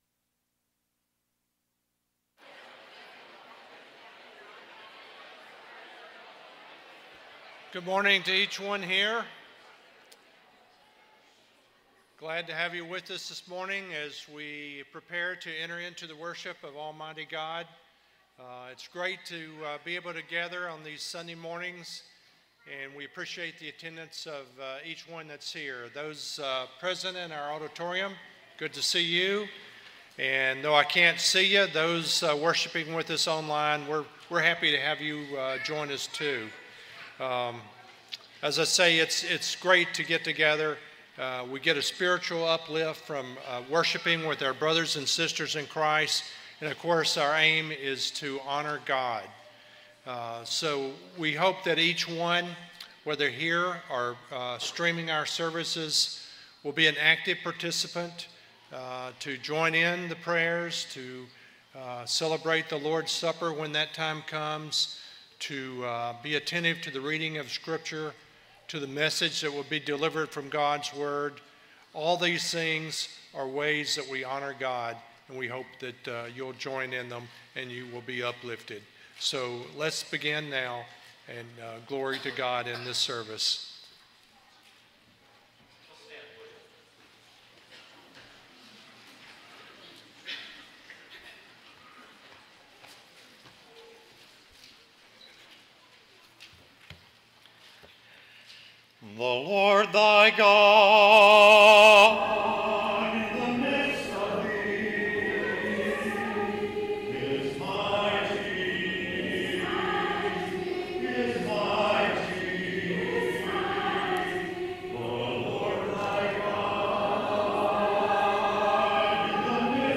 Revelation 3:14-19, English Standard Version Series: Sunday AM Service